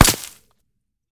dc0f4c9042 Divergent / mods / JSRS Sound Mod / gamedata / sounds / material / bullet / collide / default04gr.ogg 35 KiB (Stored with Git LFS) Raw History